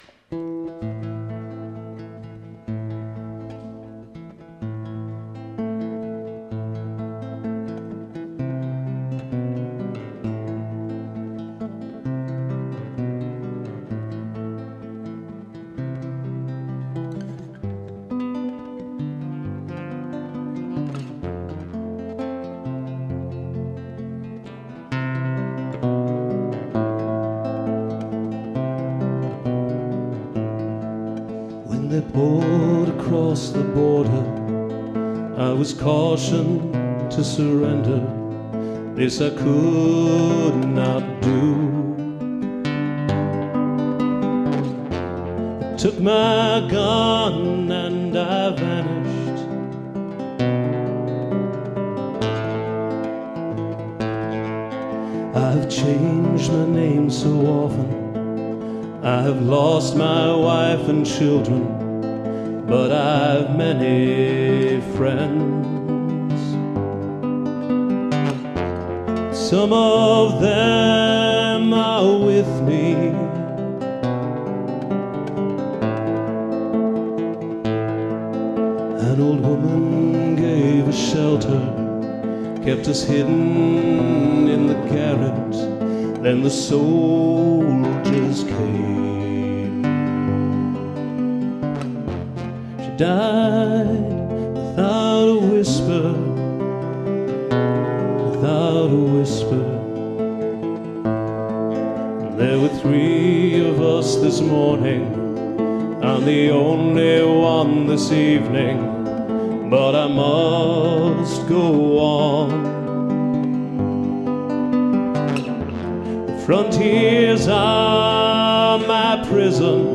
Passover Music in Poland Experience the unique spirit of the Passover season with joyous music of Passover.
The-Partisan-Live.mp3